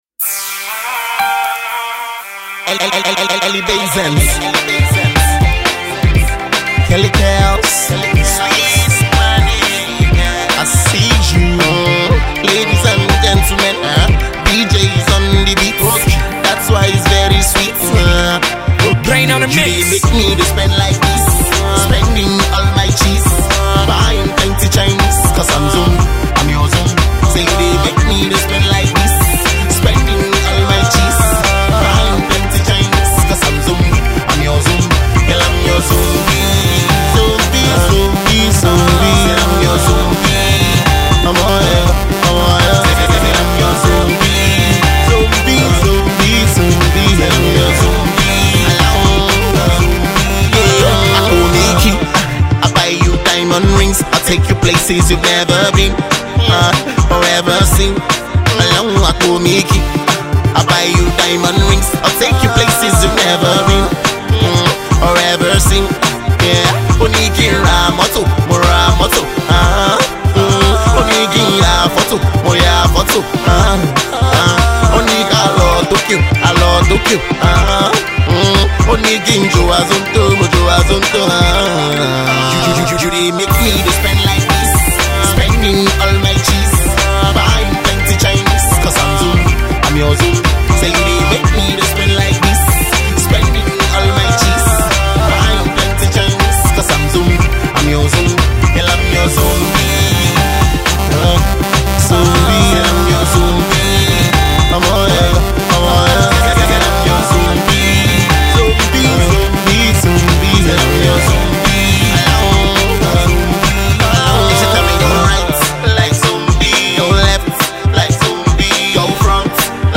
Afro-R&B singer